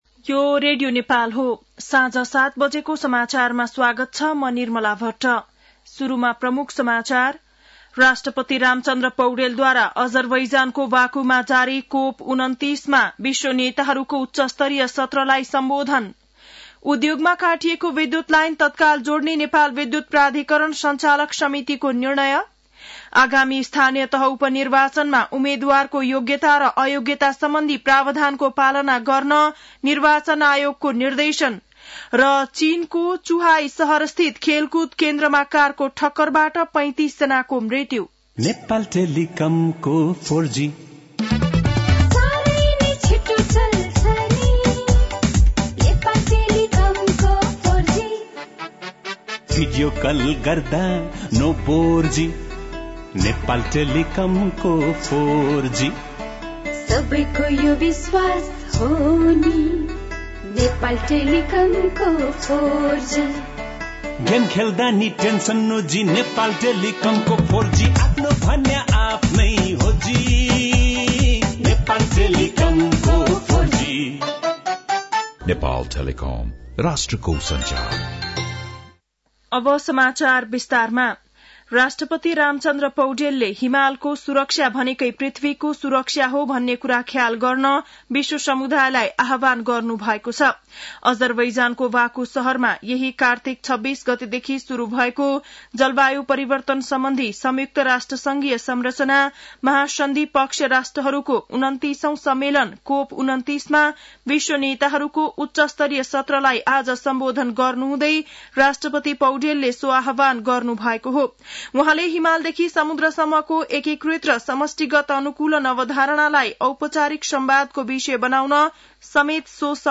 An online outlet of Nepal's national radio broadcaster
बेलुकी ७ बजेको नेपाली समाचार : २८ कार्तिक , २०८१